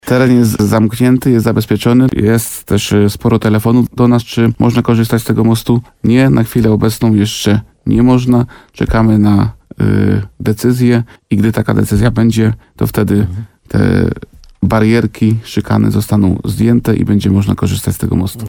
– Jeśli procedura odbioru się zakończy, to od razu poinformujemy o tym mieszkańców i udostępnimy most – powiedział w programie Słowo za Słowo wójt Bogdan Łuczkowski. W związku z licznymi próbami wjechania na most już teraz, apelował do kierowców o przestrzeganie zakazu.